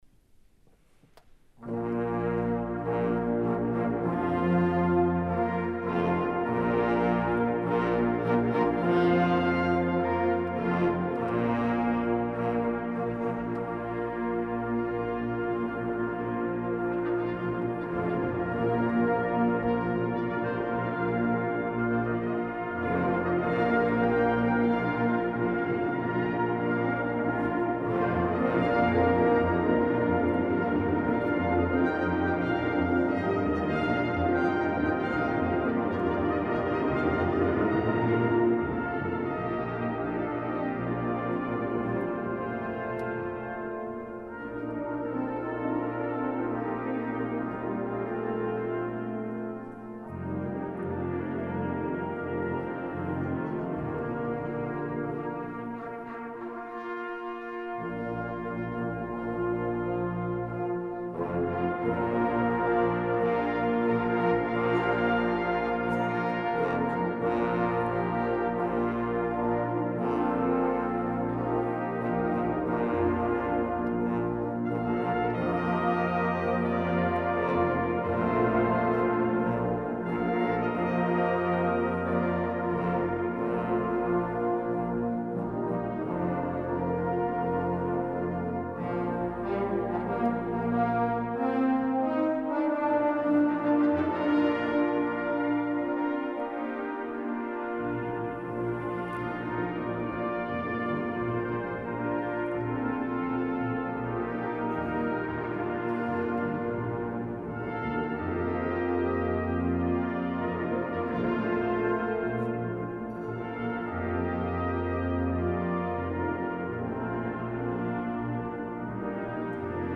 Warm, weit, strahlend – muss man selbst hören
Die Aufnahmen wurden von verschiedenen Ensembles aufgenommen und zur Verfügung gestellt.
Reinhard Gramm, Ensemble der Posaunenwarte, Gloria 2024 S. 6
03---leinen-los_reinhard-gramm_ensemble-der-posaunenwarte_gloria-s.-6.mp3